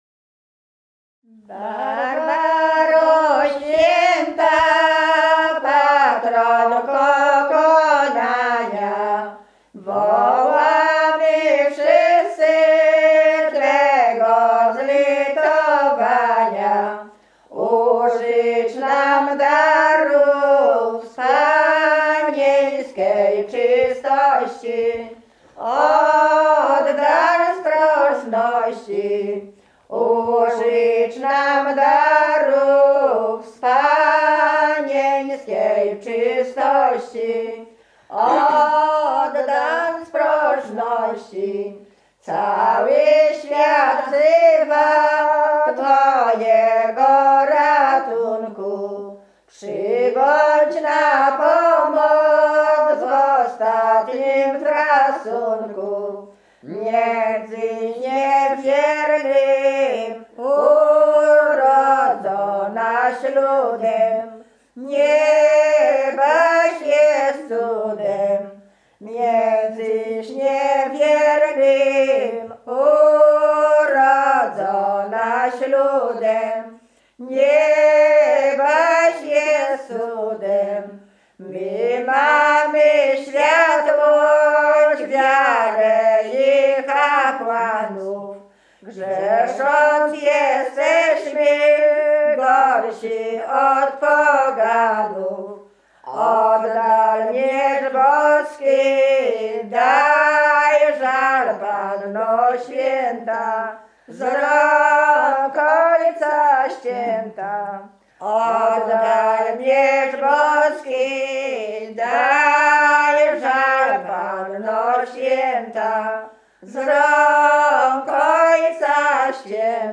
Śpiewaczki z Lubiela Starego
Kurpie
województwo mazowieckie, powiat wyszkowski, gmina Rząśnik, wieś Stary Lubiel
Pieśni o Świętych
nabożne katolickie o świętych pogrzebowe